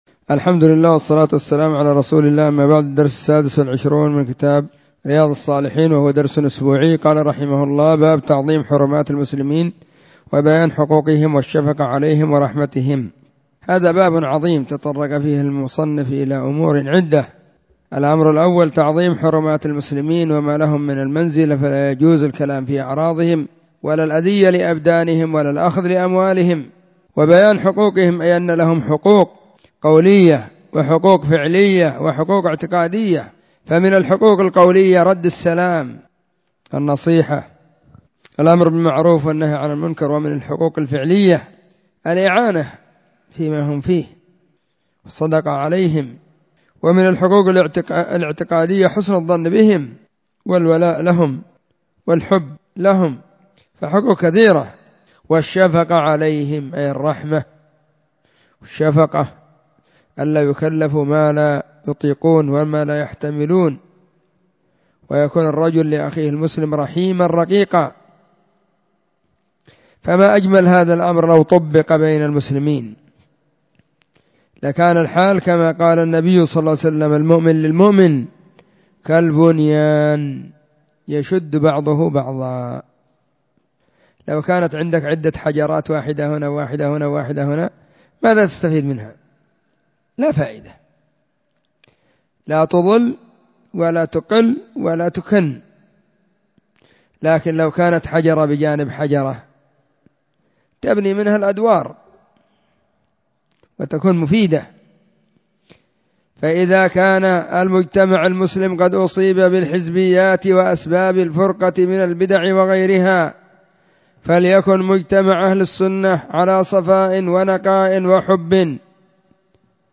🕐 [بين المغرب والعشاء في كل يوم الخميس] 📢 مسجد الصحابة – بالغيضة – المهرة، اليمن حرسها الله.